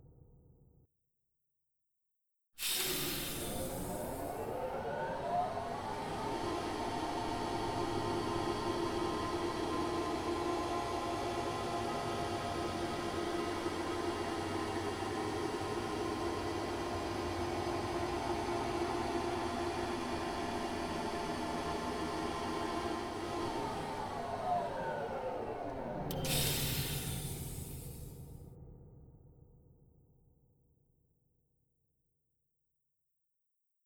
scifiElevatorDelay2sec.wav